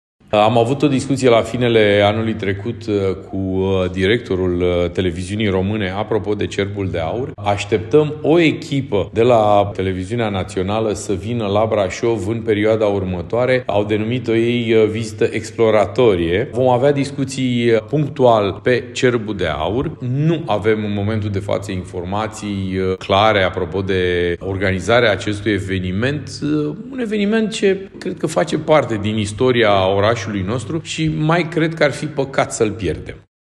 În perioada următoare vor avea loc noi discuții între municipalitate și conducerea TVR, pentru restabilirea termenilor de colaborare, potrivit viceprimarului Dan Ghiță: